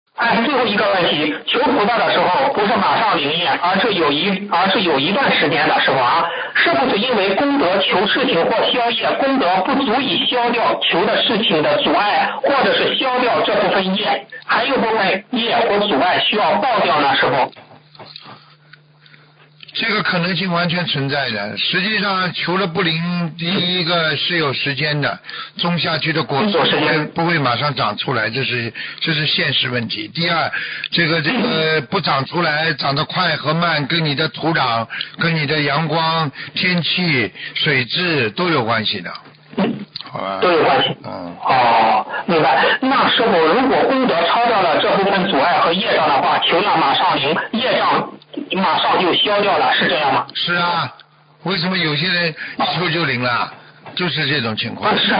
Tanya Jawab